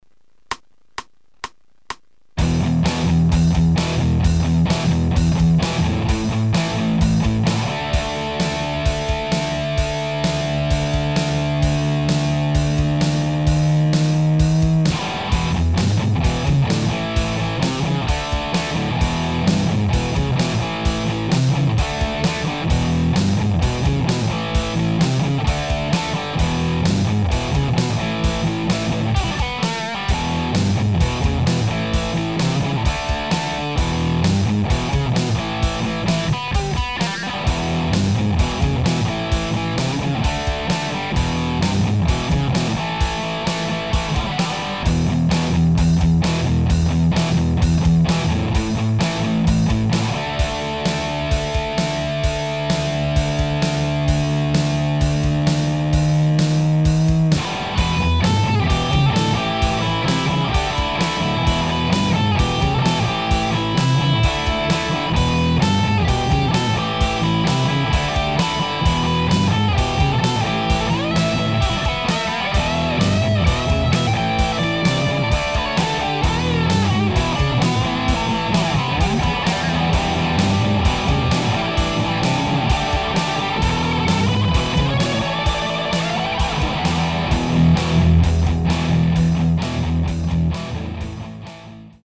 petit truc enregistré vite fait avec le GNX3... direct carte son.
Preset DIGITECH BigDuck(simu BOOGIE) et ma SilhouetteSpeciale...
j'aime pas trop ce son en fait LOL...
test_gnx3-325.mp3